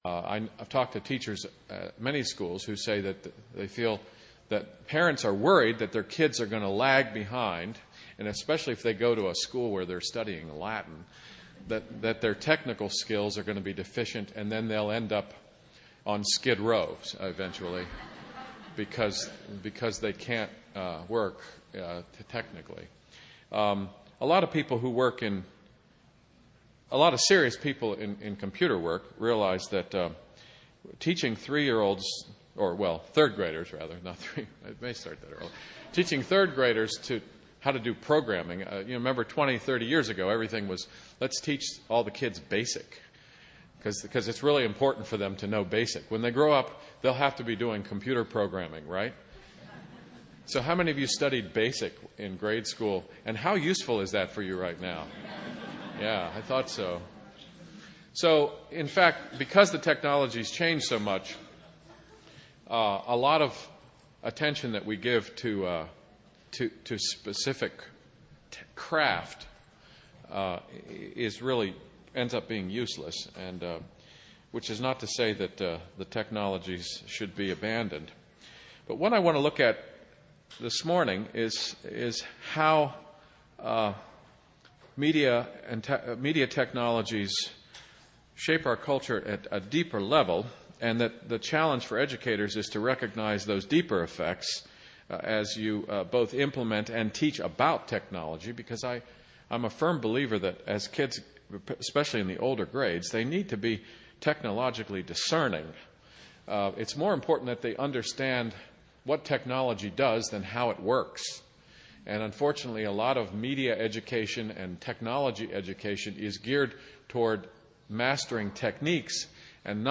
2008 Workshop Talk | 0:56:39 | All Grade Levels, Culture & Faith